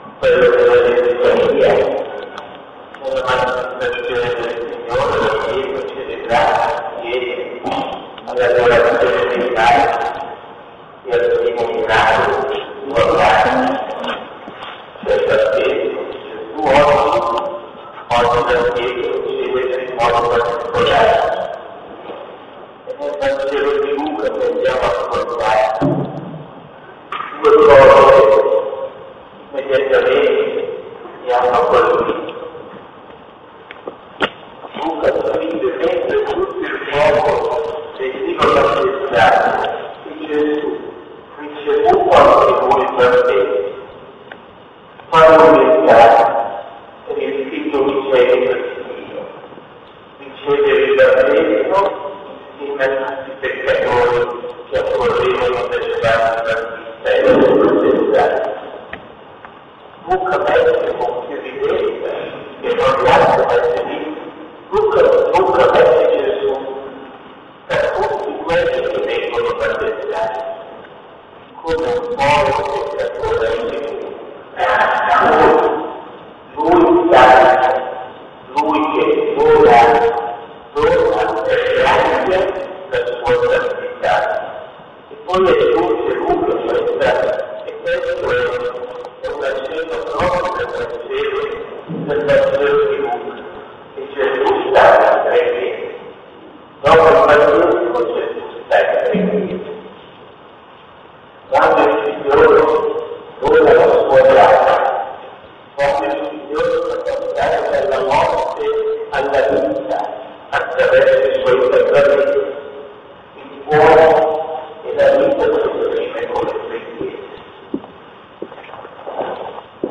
OMELIA (audio disturbato)